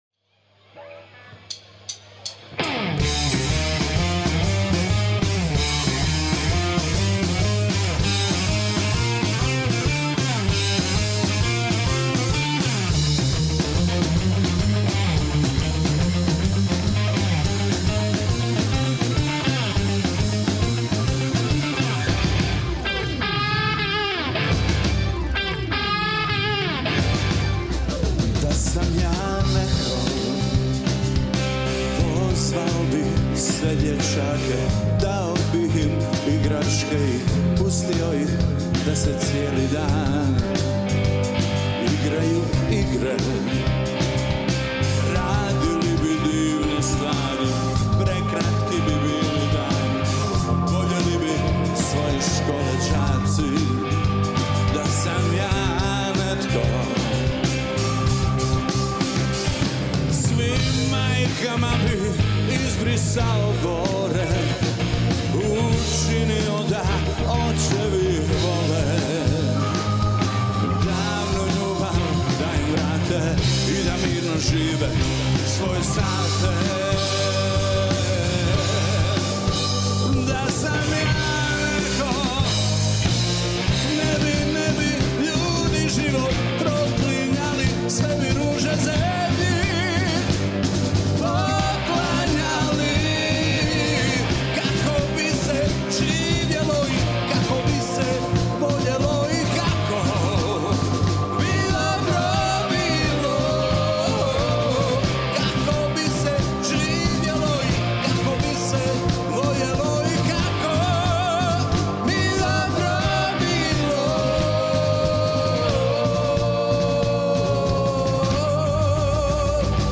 Genre Pop